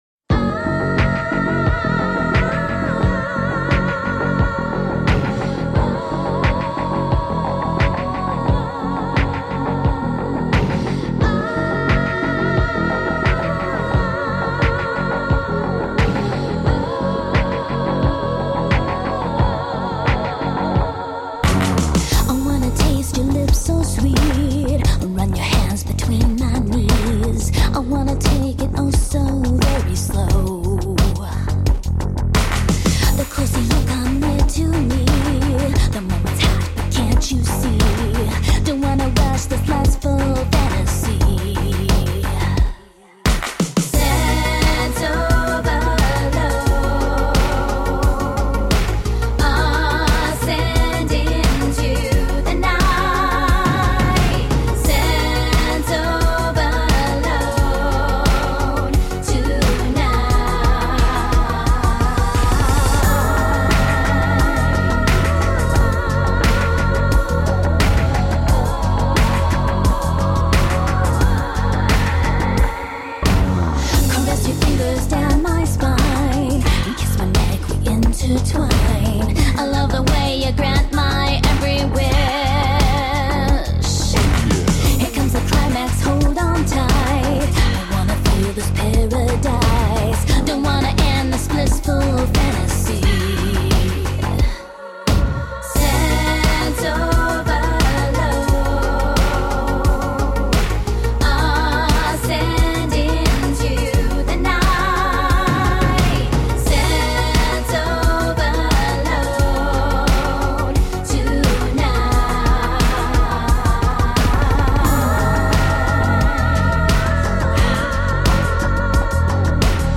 Bad ass bass with soaring femme fatale vocals.
Tagged as: Electro Rock, Pop, Woman Singing Electro Pop